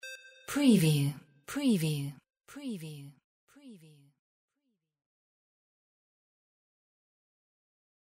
Bleep button 02
Stereo sound effect - Wav.16 bit/44.1 KHz and Mp3 128 Kbps
previewMULTI_INTERFACE_BLEEPREV_WBHD02.mp3